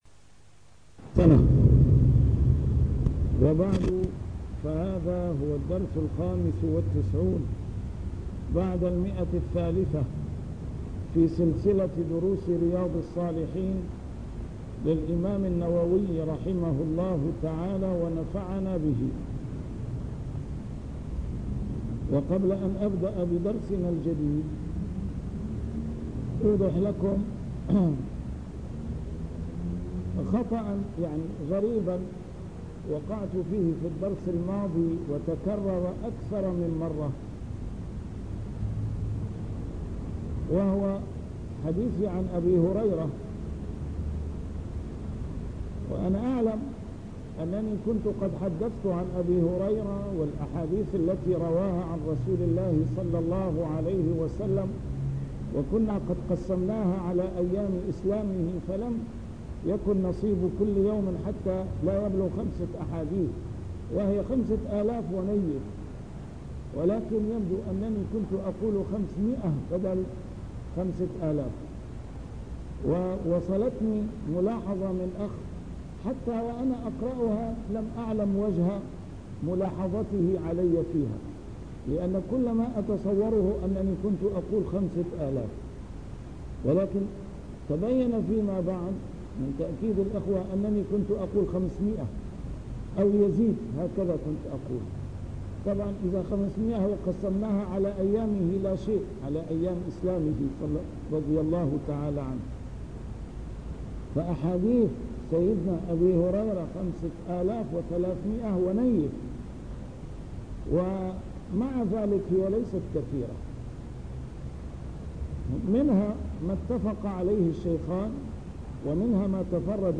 A MARTYR SCHOLAR: IMAM MUHAMMAD SAEED RAMADAN AL-BOUTI - الدروس العلمية - شرح كتاب رياض الصالحين - 395- شرح رياض الصالحين: أمر الرجل أهله بطاعة الله